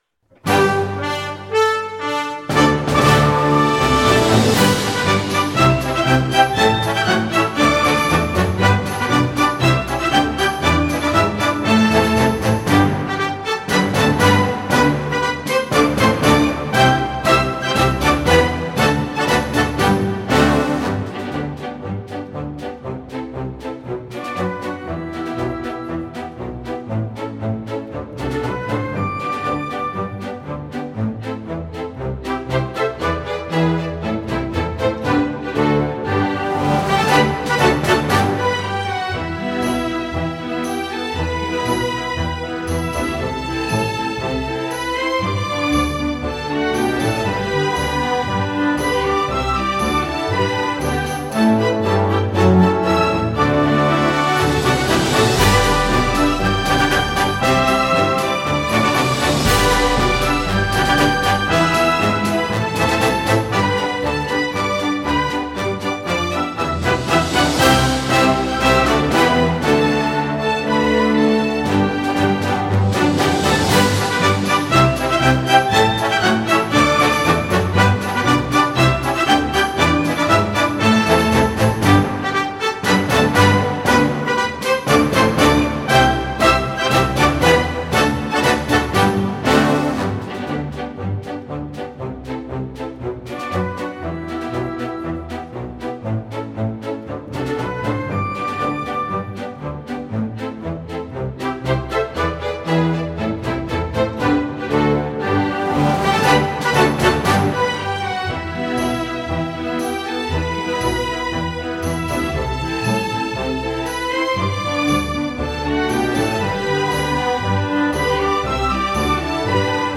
总体以进行曲为曲调，气势雄壮、节奏铿锵、催人奋进